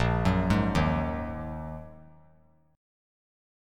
A#7sus4#5 Chord
Listen to A#7sus4#5 strummed